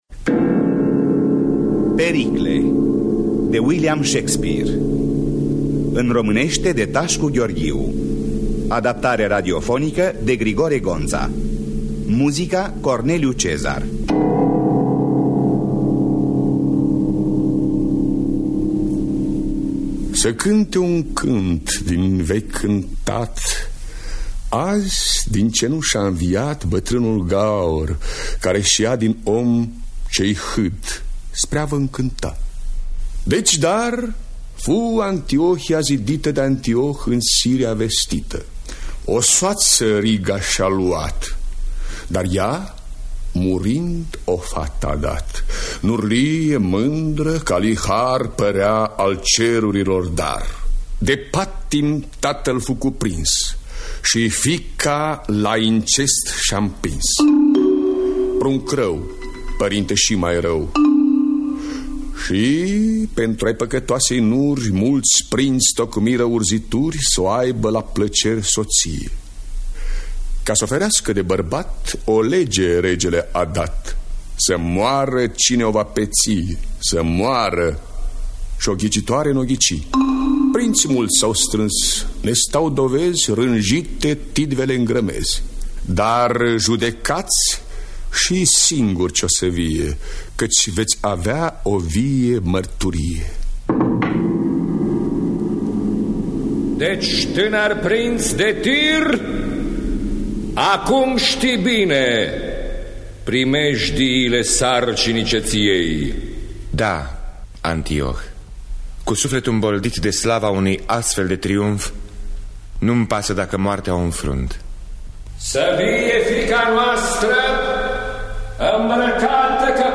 Adaptarea radiofonică
Înregistrare din anul 1973.